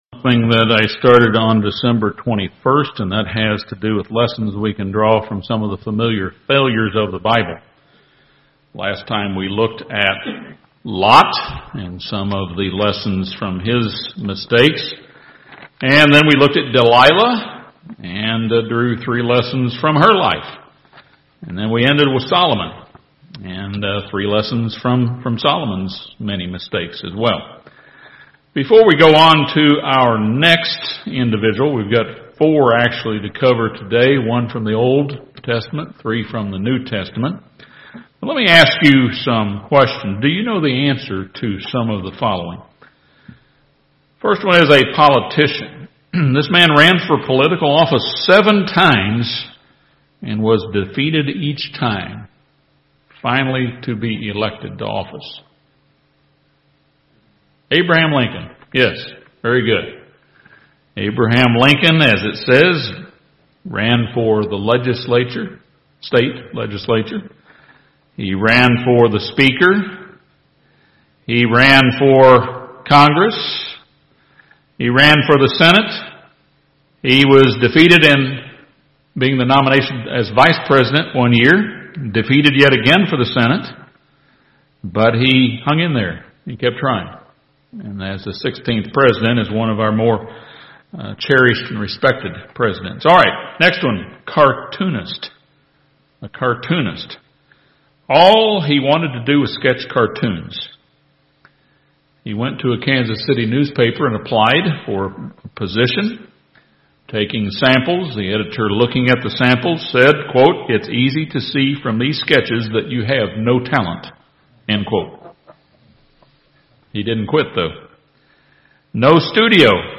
This sermon looks at the examples of Ahab, Martha, Pontius Pilate and Peter. Two lessons are drawn from each person’s familiar failures.